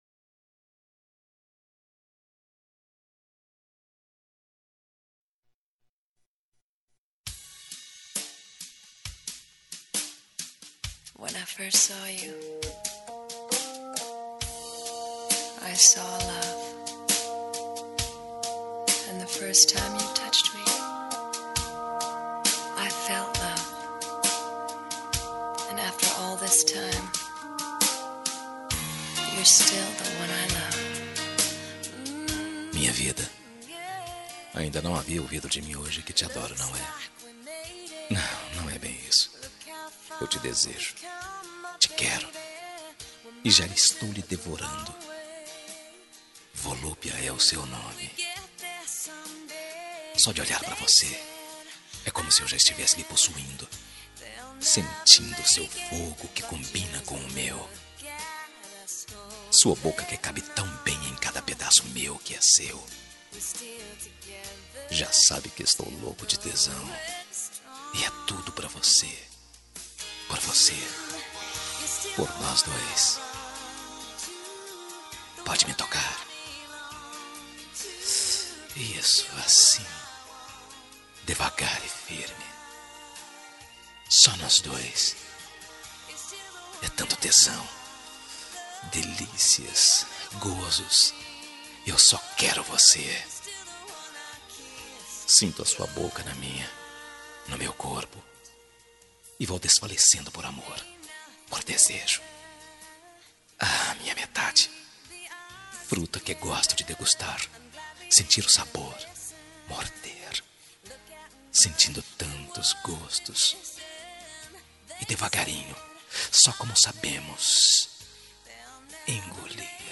Telemensagem Picante – Voz Masculina – Cód: 79220